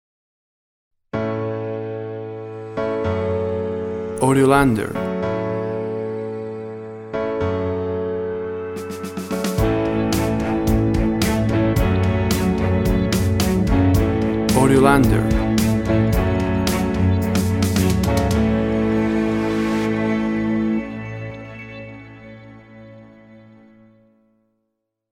A happy and energetic melody, accompanied with brigth pads.
Tempo (BPM) 108